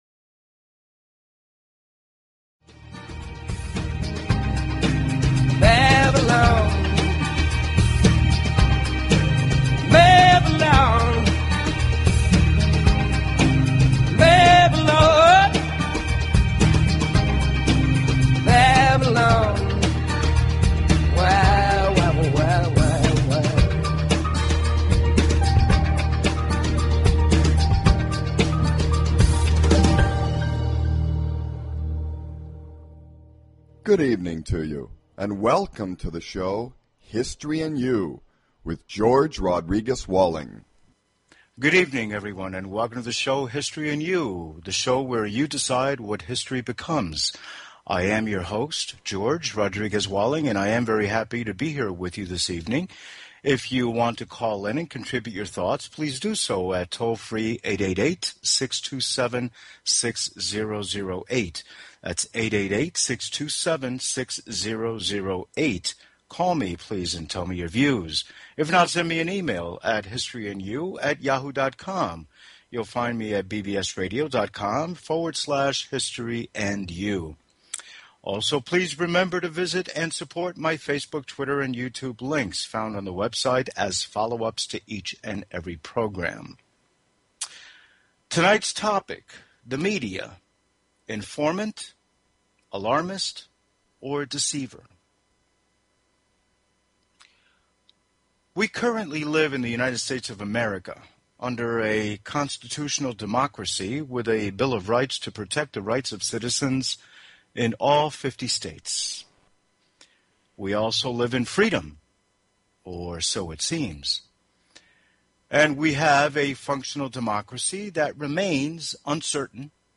Guest: Dr. Noam Chomsky